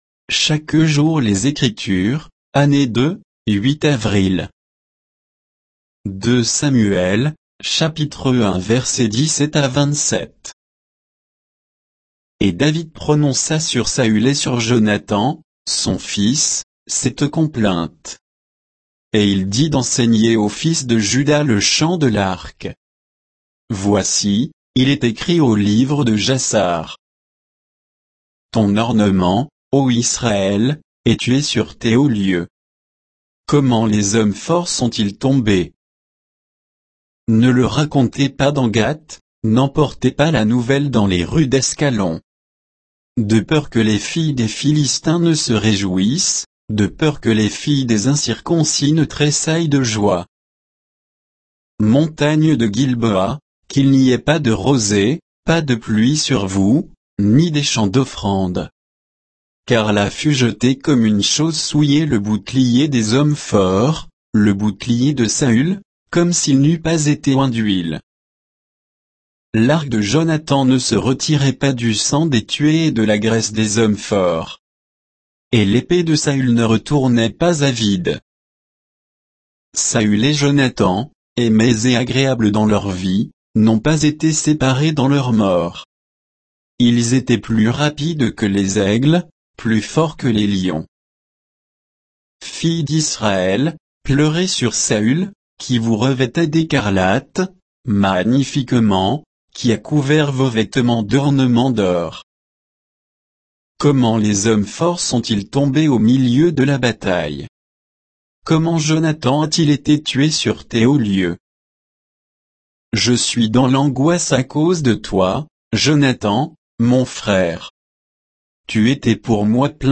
Méditation quoditienne de Chaque jour les Écritures sur 2 Samuel 1, 17 à 27